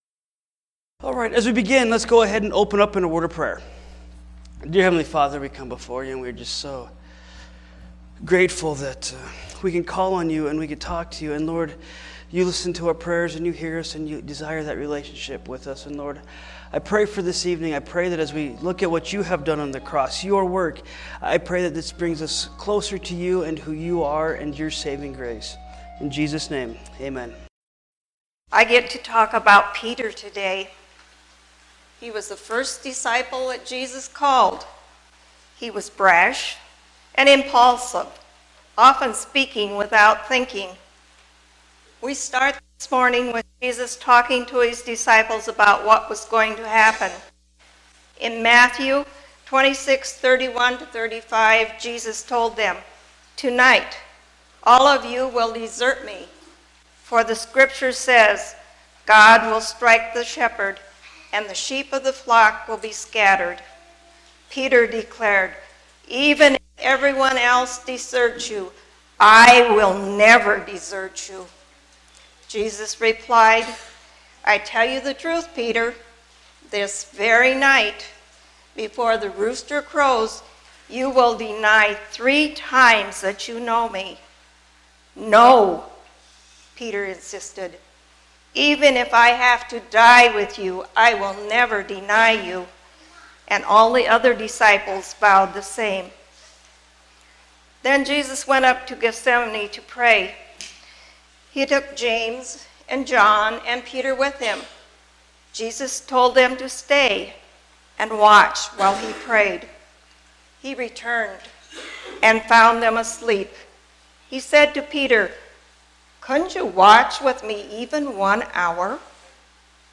Easter Service
4-21-19-Easter-Service.mp3